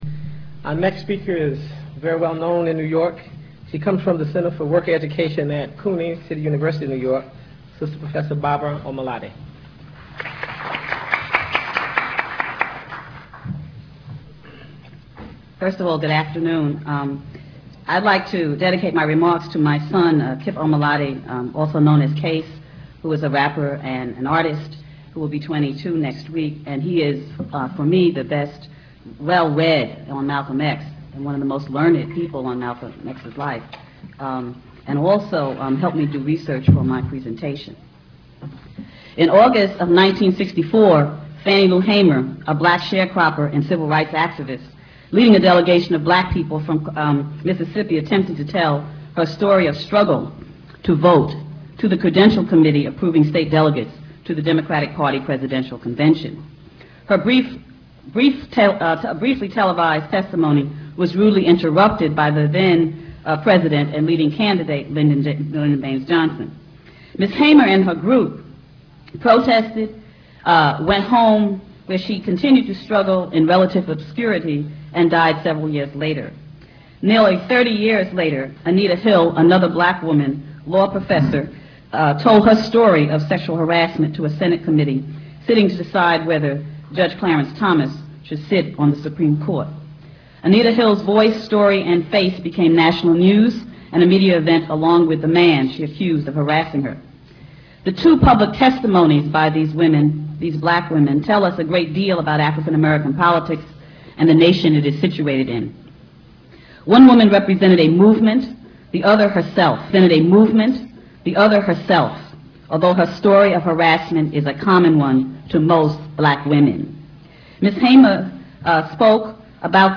This webpage contains sound files to the 1991 International conference on Malcolm X held in New York City at the Bourough of Manhattan Community College.